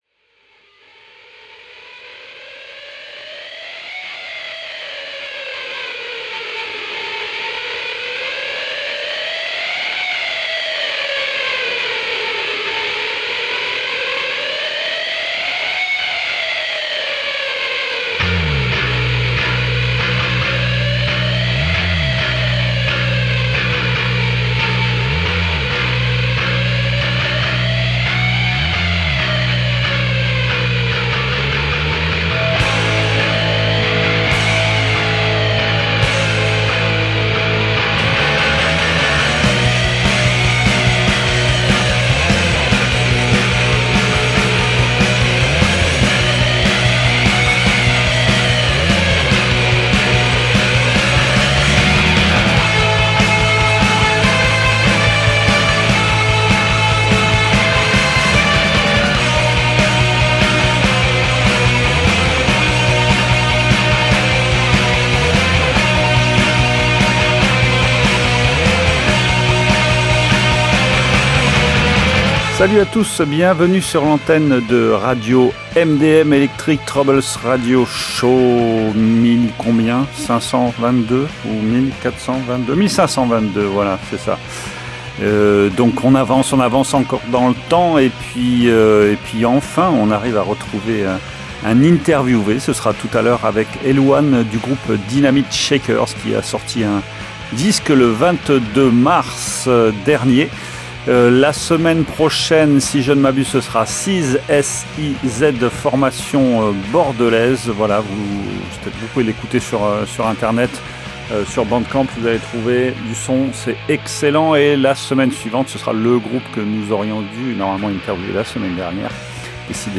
La relève rock’n’roll ?